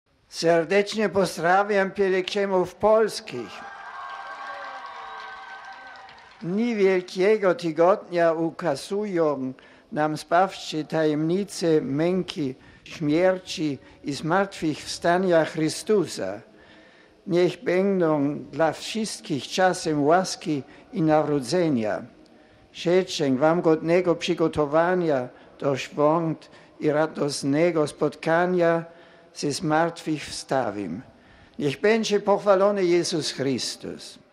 Papieskie pozdrowienia w języku polskim: RealAudio